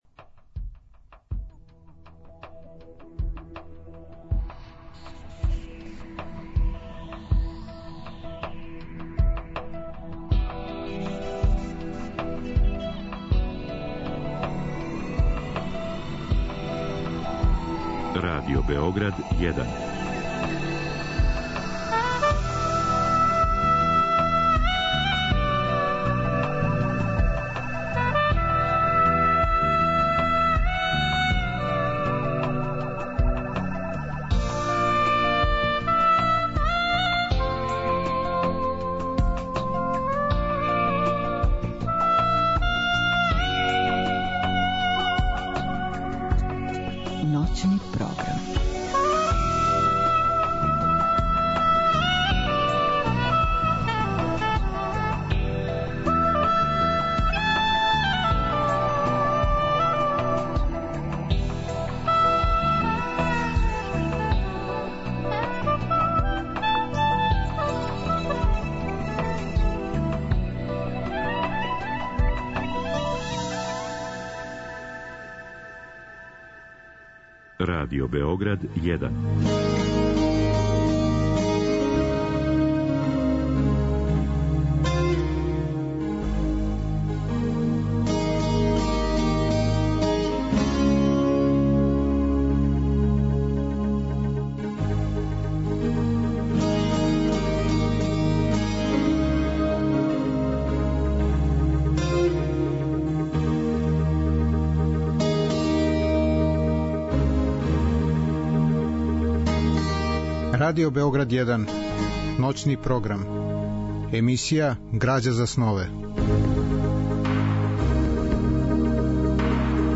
Разговор и добра музика требало би да кроз ову емисију и сами постану грађа за снове.
У другом делу емисије, од два до четири часа ујутро, слушаћемо одабране делове из ових радио-драматизација дела Милоша Црњанског. Чућемо фрагменте из радио-драма рађених по делу Сеобе, Сеобе друга књига, Лирика Итаке и Дневник о Чарнојевићу.